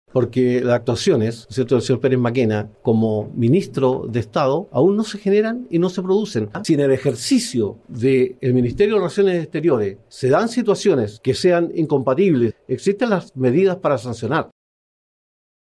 En conversación con Bío Bío TV, Alvarado defendió ambas designaciones y descartó cualquier irregularidad o conflicto de interés, subrayando que se trata de procesos habituales en la conformación de un gabinete presidencial.